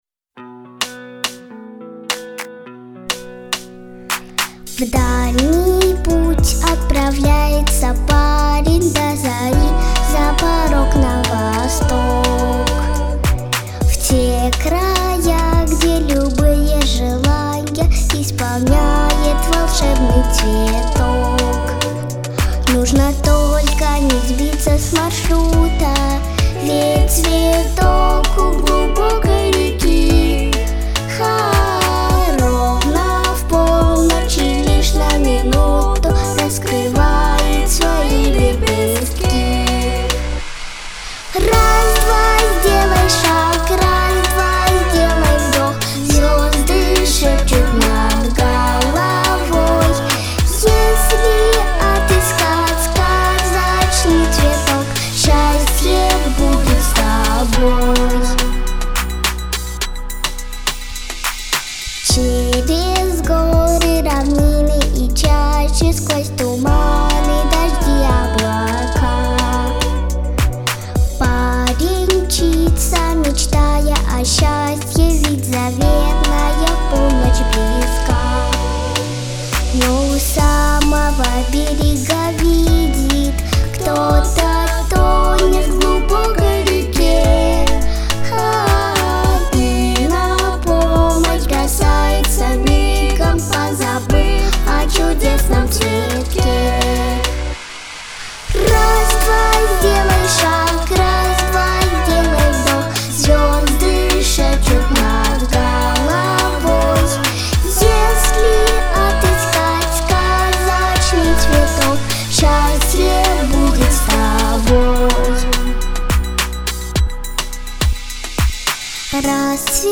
Характер песни: позитивный, лирический.
Темп песни: средний.